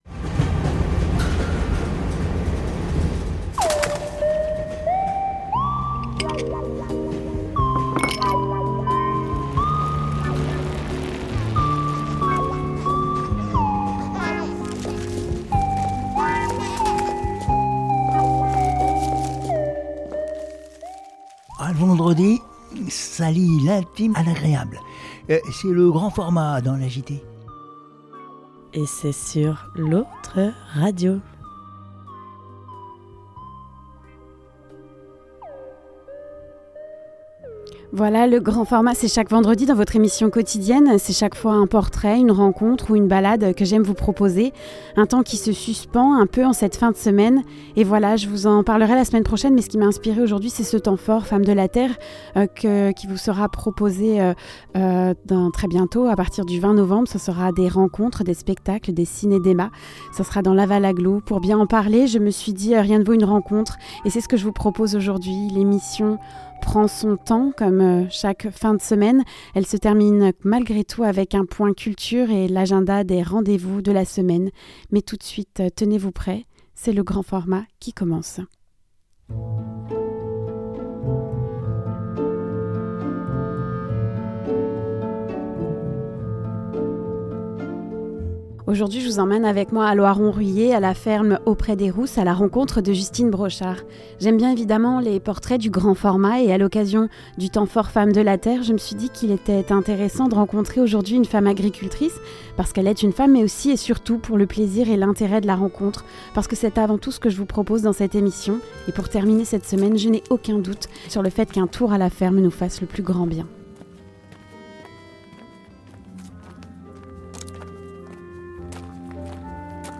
C'est un portrait de femme, une rencontre que l'on vous propose aujourd'hui, dans cette émission.